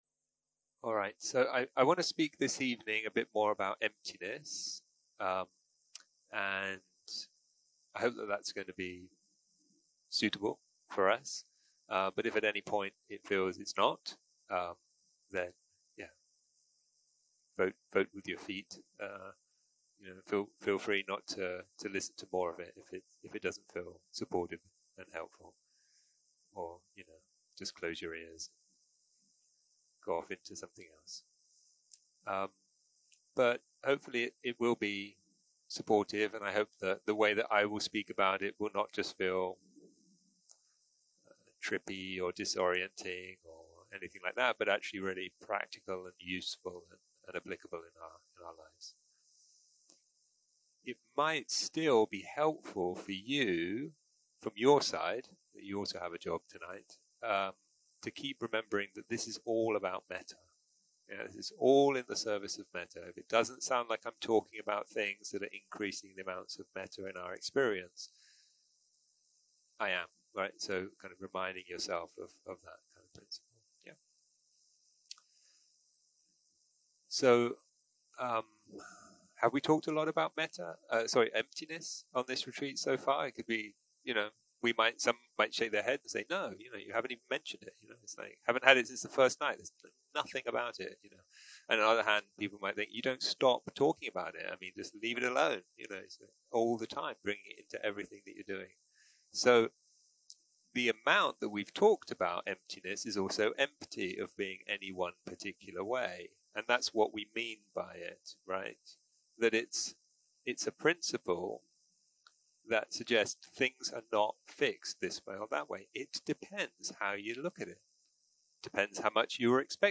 יום 5 - הקלטה 18 - ערב - שיחת דהרמה - ריקות, אנאטה
סוג ההקלטה: שיחות דהרמה